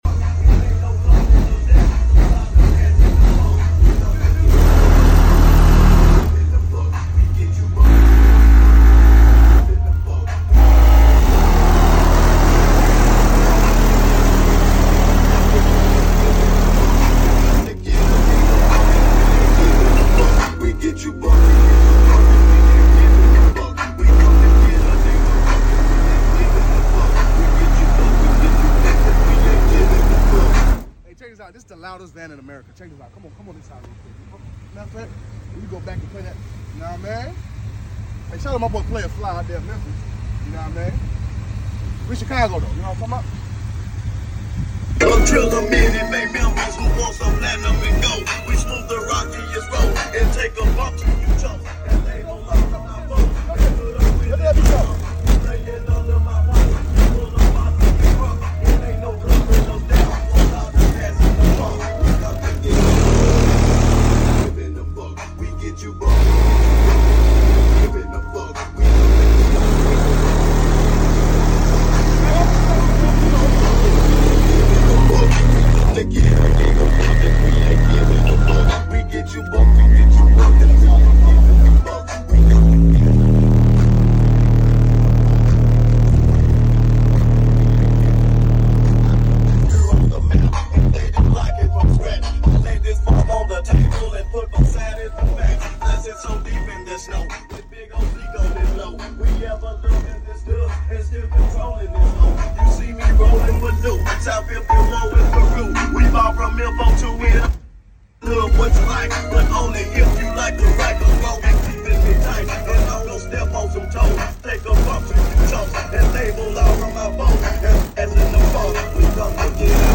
The loudest van in America sound effects free download